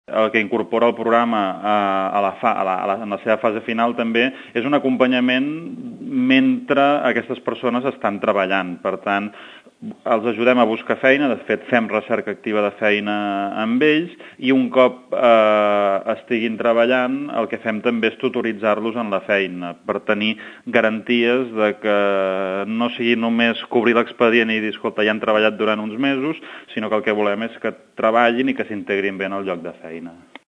Segons el regidor, el programa també incorpora un acompanyament mentre els joves estan treballant.